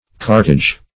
Cartage \Cart"age\, n.